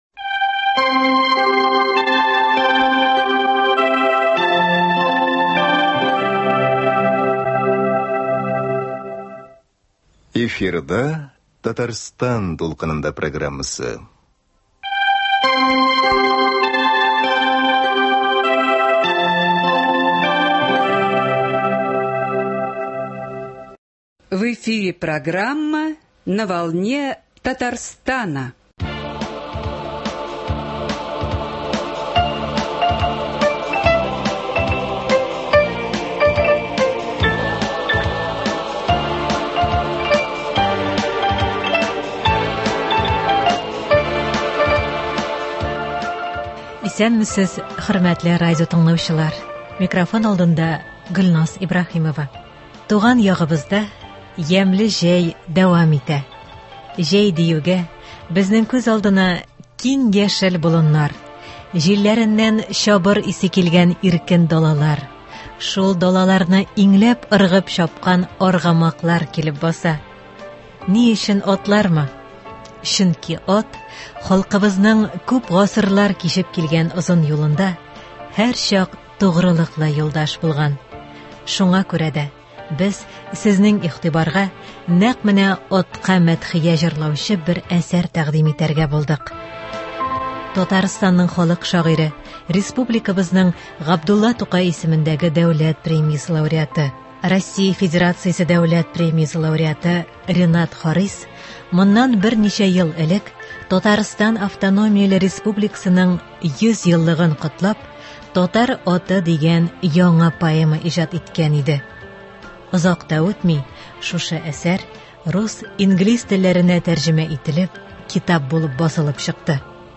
Радиокомпозиция.